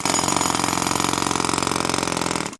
Jackhammer Short Hammering Of Concrete